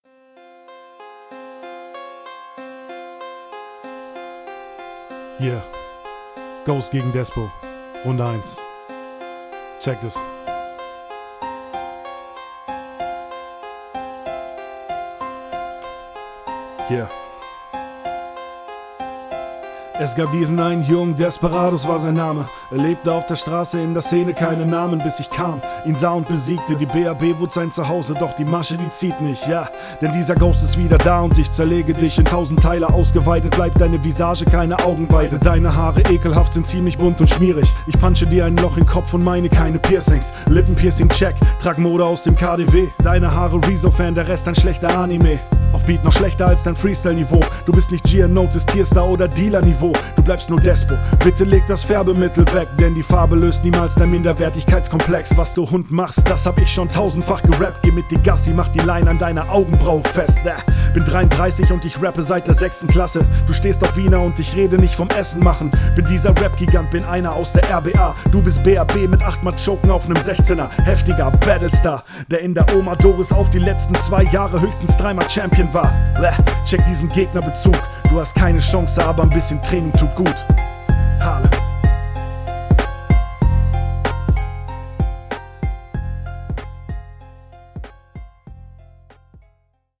Wie erwartet mega solide runde,deine Stimme ist der Ultra Shit! feier ich …
Beat kommt sehr entspannt, dein rap gefällt mir auch.